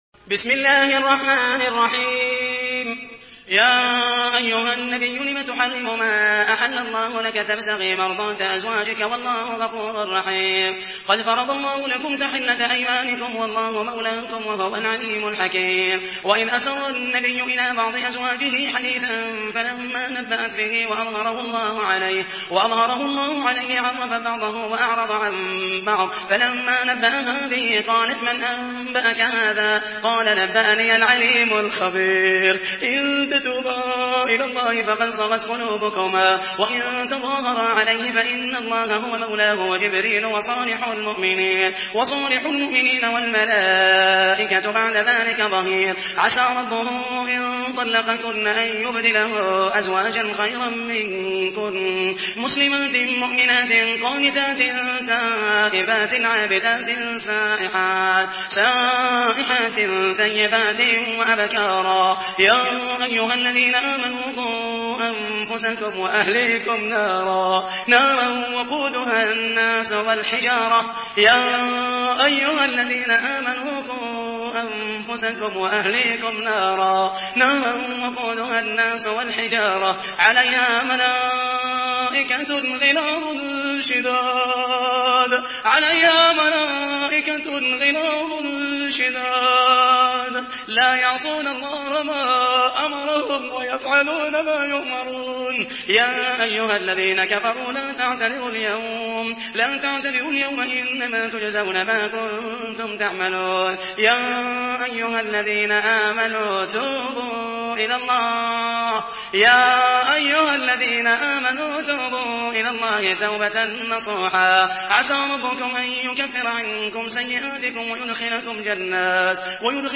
سورة التحريم MP3 بصوت محمد المحيسني برواية حفص
مرتل حفص عن عاصم